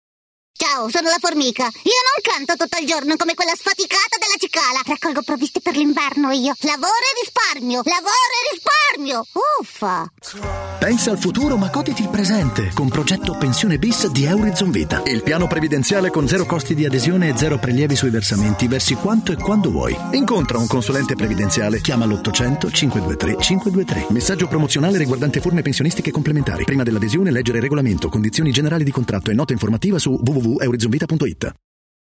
attore doppiatore